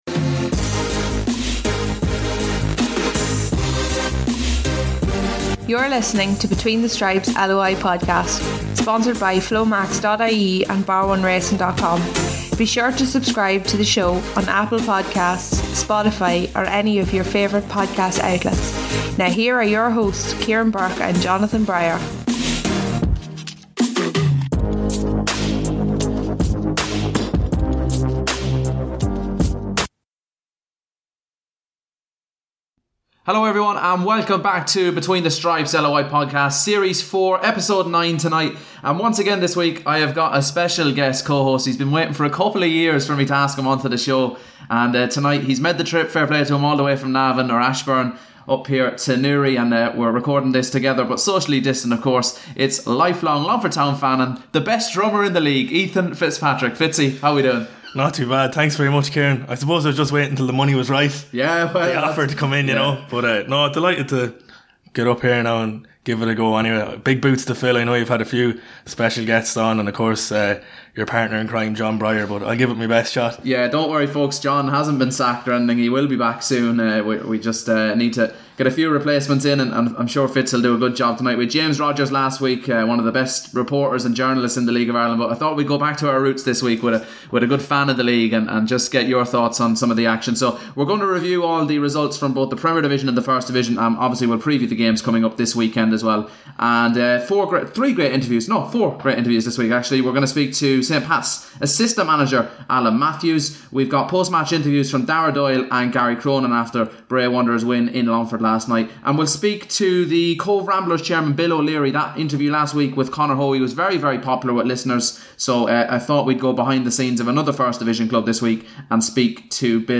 discuss the latest hot topics and bring you 4 top class interviews once again.